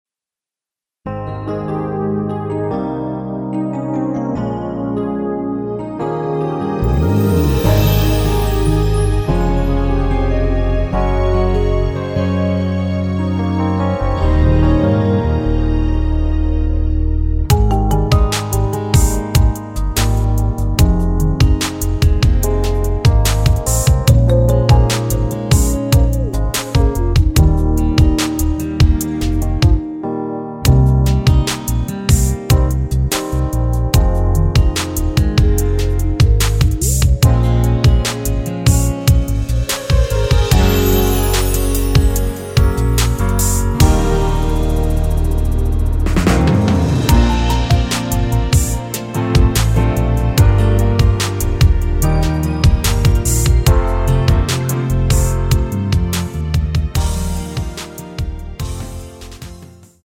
Ab
◈ 곡명 옆 (-1)은 반음 내림, (+1)은 반음 올림 입니다.
앞부분30초, 뒷부분30초씩 편집해서 올려 드리고 있습니다.
중간에 음이 끈어지고 다시 나오는 이유는